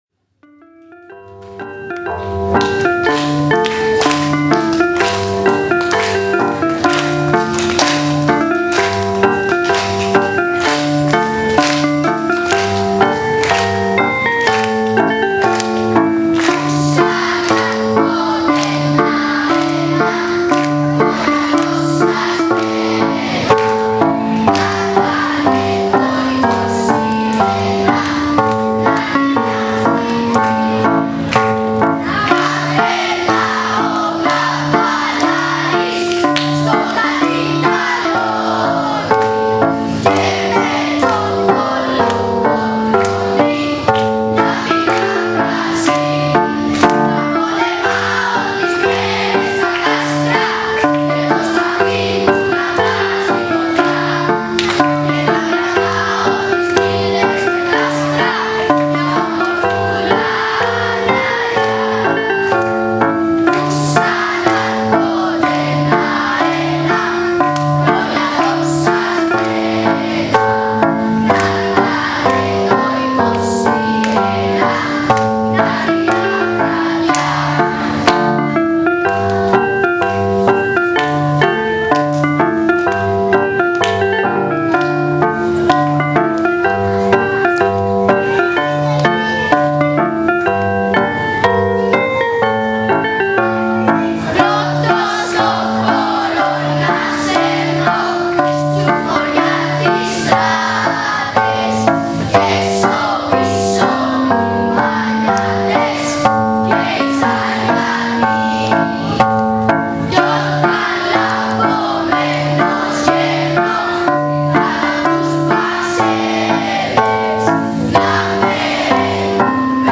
Για όλους εμάς που απολαύσαμε τους μαθητές μας στο κείμενο και τα τραγούδια της παράστασης για την επέτειο της 25ης Μαρτίου αλλά και για όλους αυτούς που δεν είχαν την ευκαιρία να τα παρακολουθήσουν, ανεβάζουμε ορισμένα από τα τραγούδια της παράστασης, όπως τα ερμήνευσε η χορωδία των μαθητών υπό την καθοδήγηση της μουσικού του σχολείου μας. [Η ανισομέρεια του επιπέδου έντασης της μουσικής και των τραγουδιών οφείλεται σε λάθος θέση της συσκευής καταγραφής του ήχου.